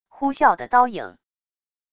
呼啸的刀影wav音频